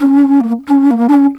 Flute 51-07.wav